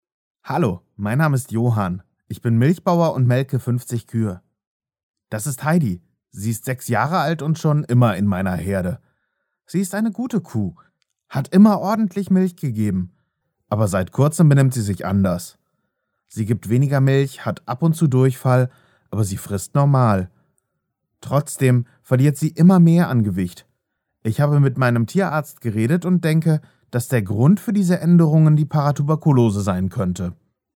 Die Deutsche Stimme mit Variation und viel Charakter
Kein Dialekt
Sprechprobe: eLearning (Muttersprache):